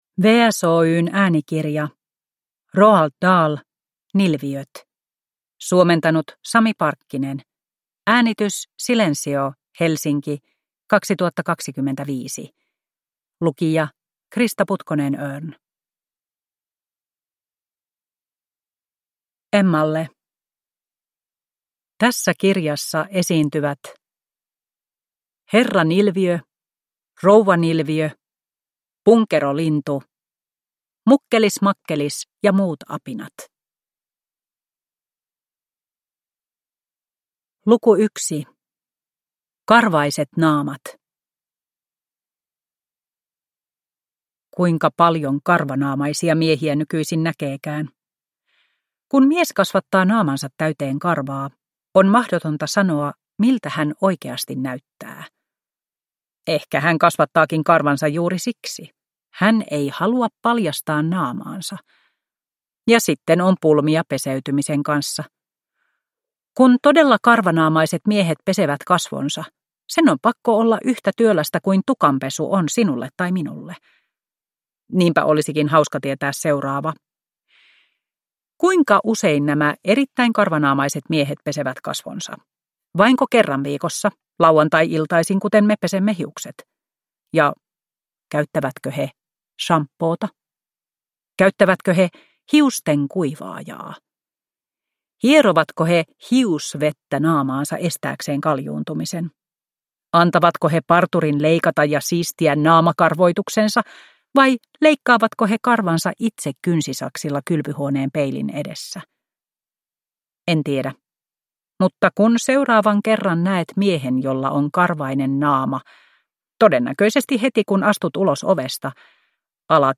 Nilviöt – Ljudbok